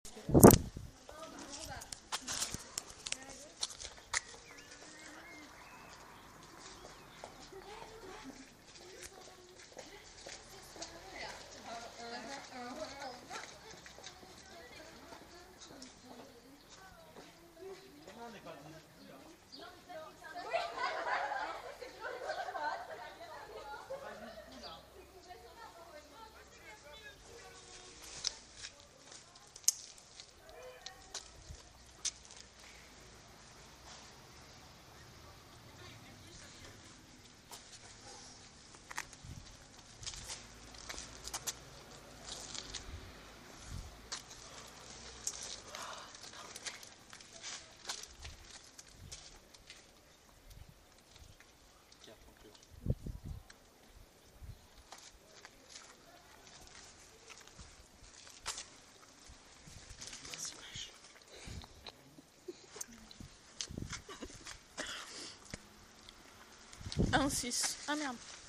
Mairie de Rilhac Rancon
Voiture